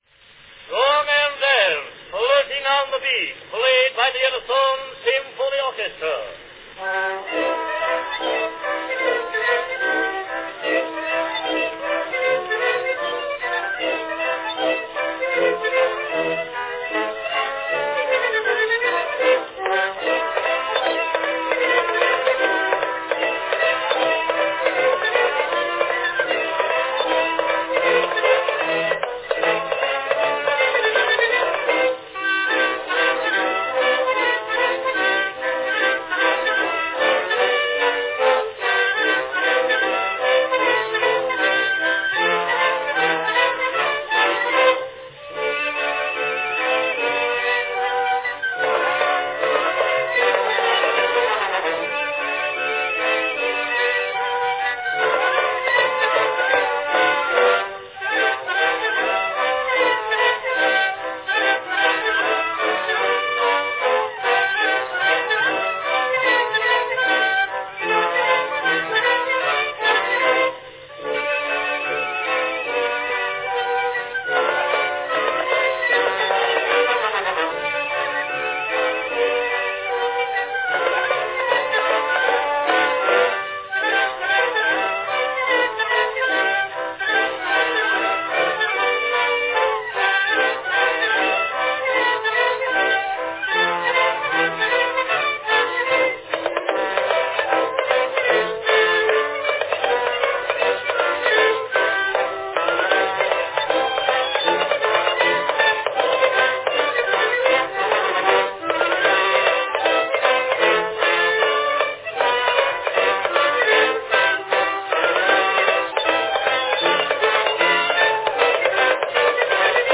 Category Orchestra
Performed by Edison Symphony Orchestra
Announcement "Song and dance, Flirting on the Beach, played by the Edison Symphony Orchestra."
Enjoy this great 1890's-era tune, originally released on brown wax, here remade by Edison on black wax.